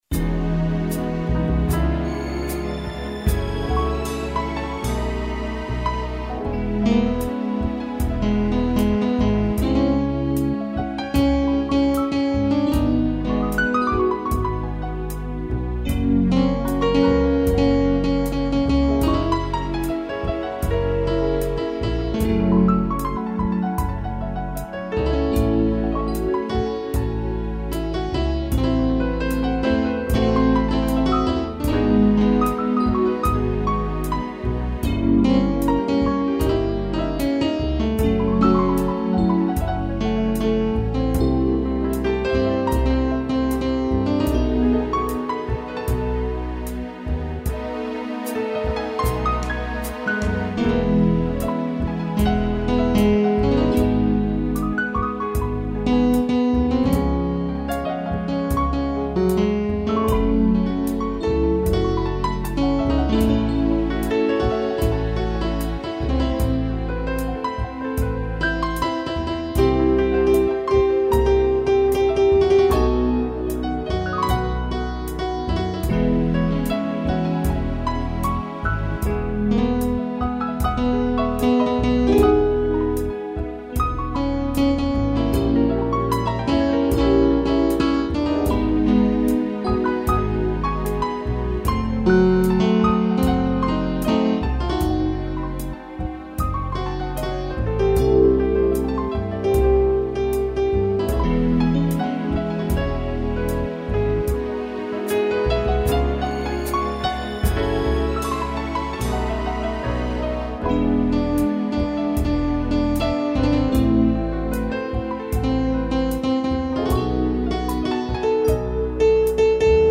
piano
(instrumental)